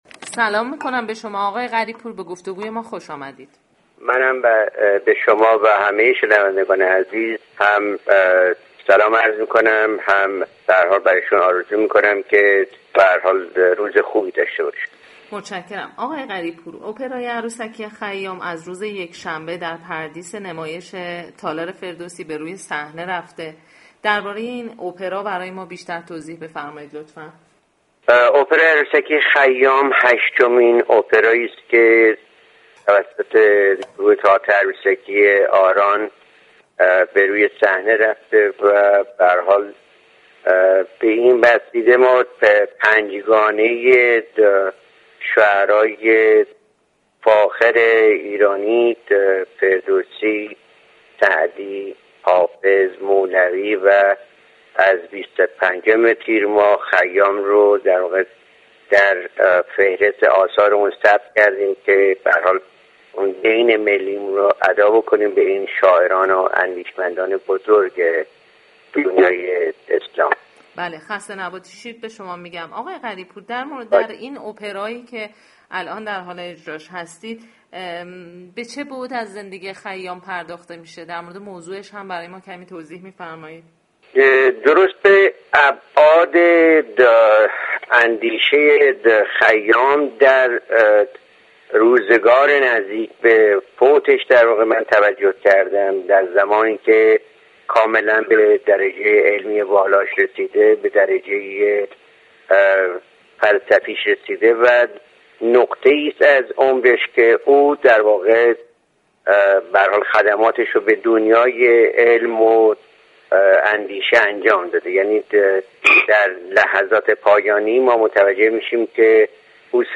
بهروز غریب پور طراح ، نویسنده و كارگردان این اپرا در گفتگوی اختصاصی با سایت شبكه فرهنگ درباره ی جزییات این اپرای عروسكی گفت : اپرای عروسكی خیام هشتمین اپرایی است كه توسط گروه تئاتر عروسكی «آران» به روی صحنه رفته است و به این ترتیب ما 5 گانه ی شعرای فاخر ایرانی ، فردوسی ، سعدی ، حافظ ، مولوی و خیام را در فهرست آثارمان ثبت كردیم .